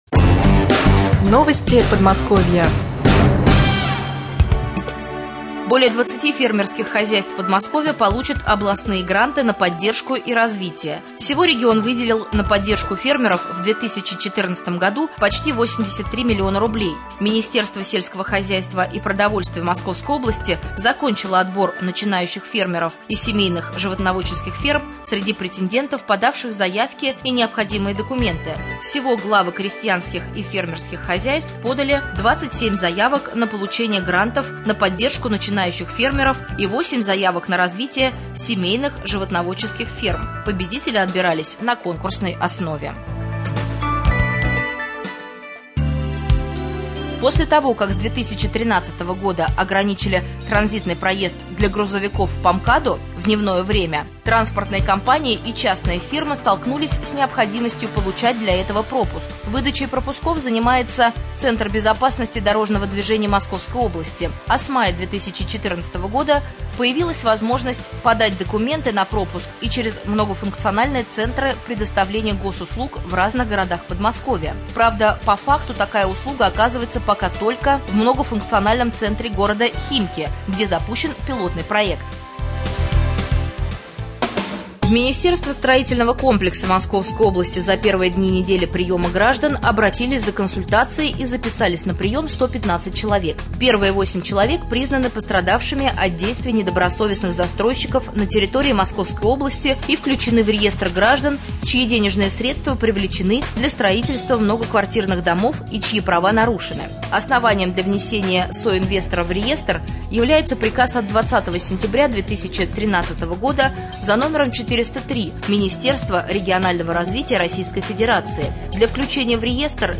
07.08.2014г. в эфире Раменского радио - РамМедиа - Раменский муниципальный округ - Раменское